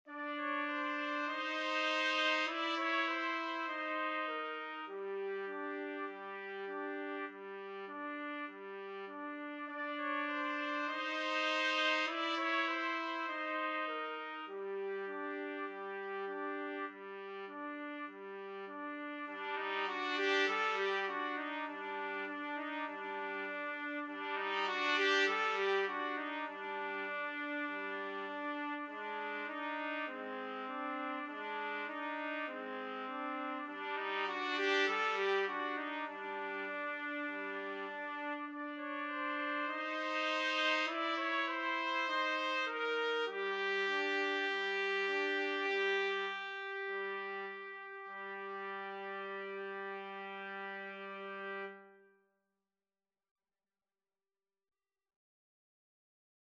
A hint of James Bond and a hint of Egypt in this piece.
4/4 (View more 4/4 Music)
Trumpet Duet  (View more Easy Trumpet Duet Music)
Jazz (View more Jazz Trumpet Duet Music)